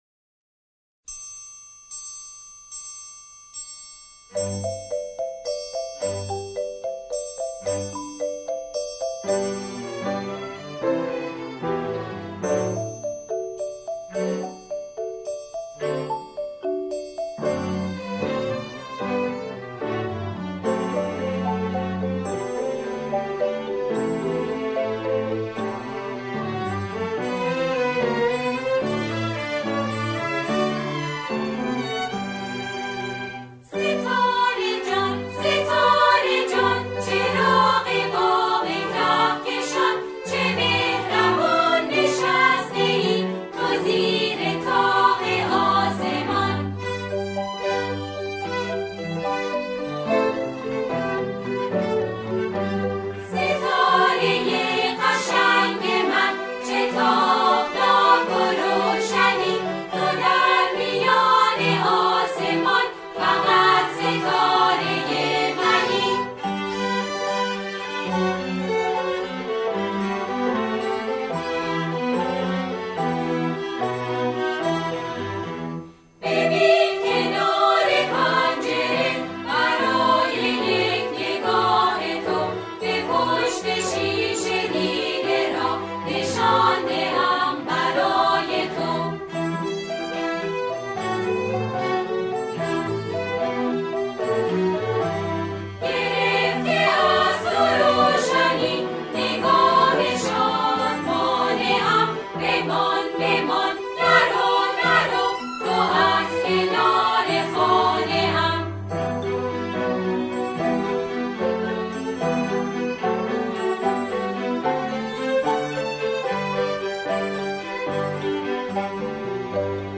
سرود کودکانه